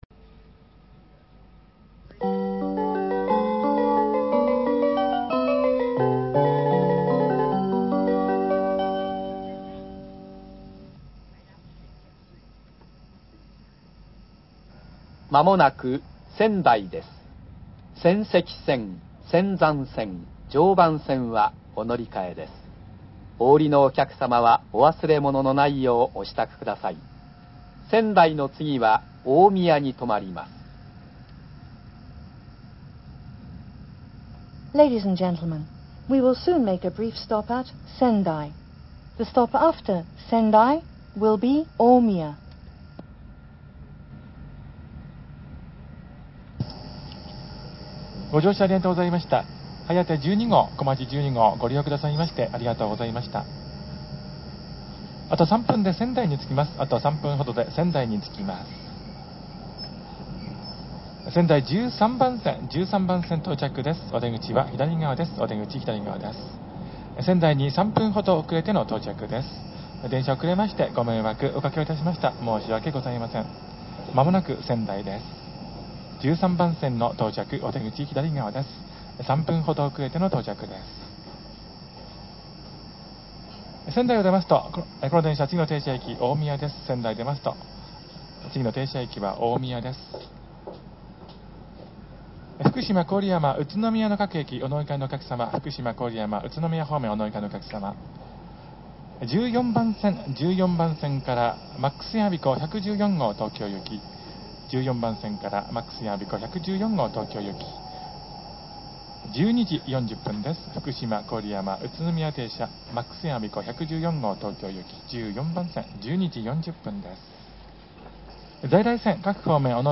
ＪＲ東日本 車内放送
仙台発車後大宮到着前上野到着前東京到着前   東北・山形・秋田新幹線チャイム
hayate12go&komachi12go-sendai.mp3